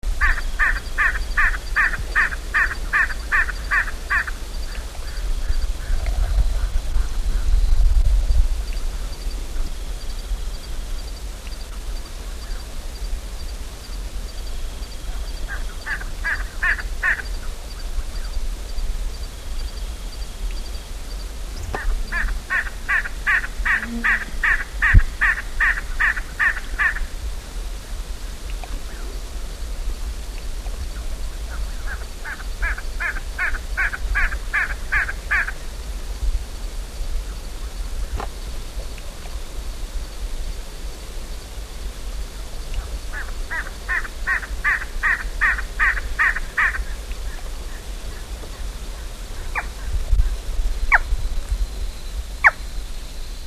As dusk sets in and the diurnal birds are settling down to roost, a mysterious and slightly nasal “waka-waka-waka-waka” can be heard emanating from the ground in dense grasslands in eastern Paraguay.
Gallinagoundulata3.mp3